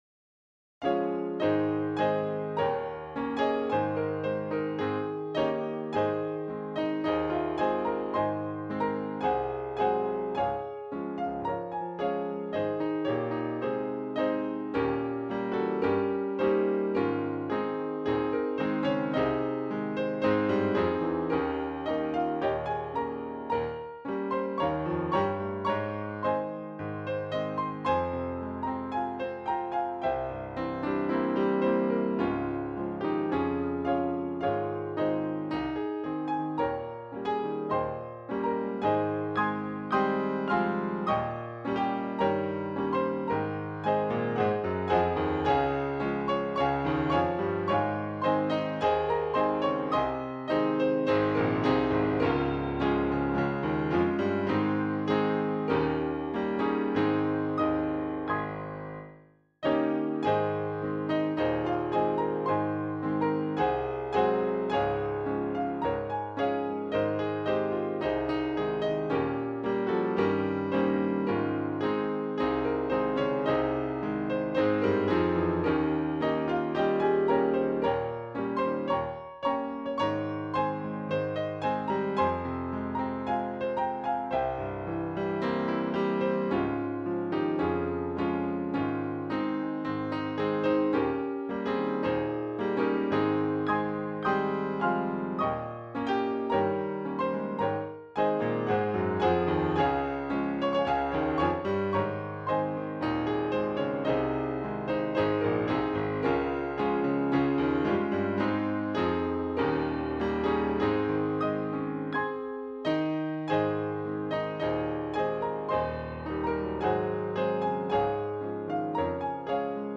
Subjects: Gospel
Key: A♭